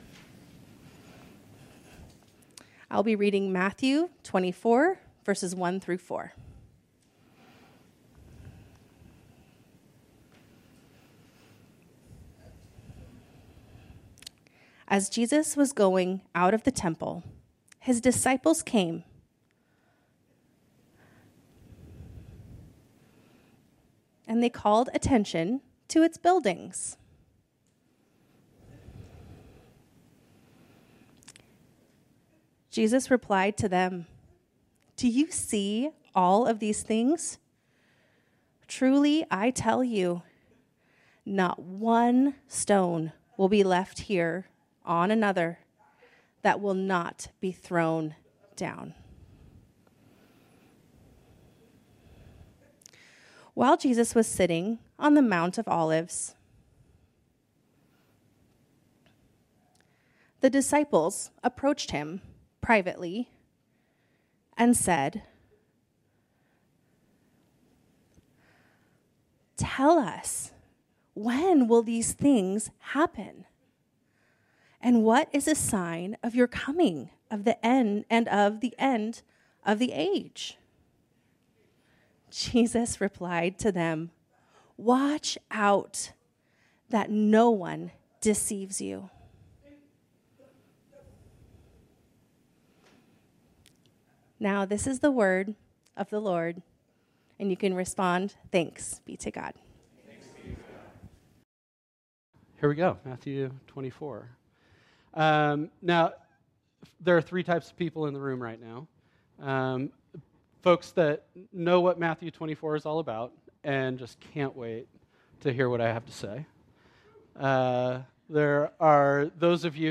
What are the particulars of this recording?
This sermon was originally preached on Sunday, October 20, 2024.